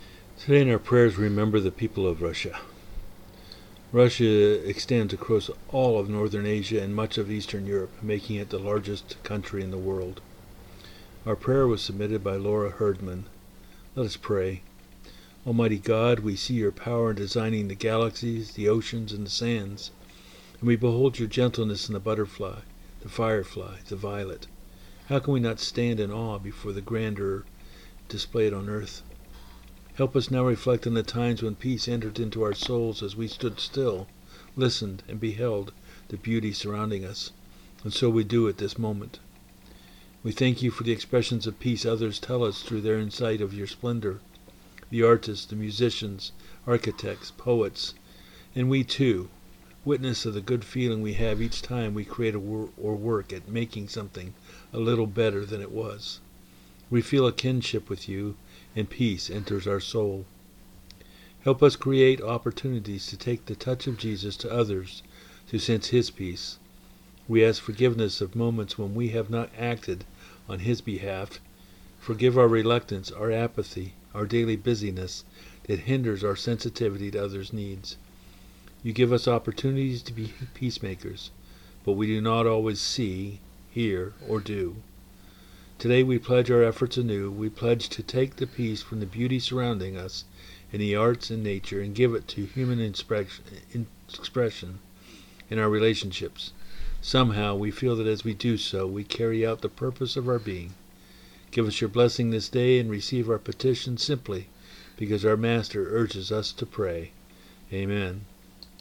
January 22 2023 Service
Prayer for Peace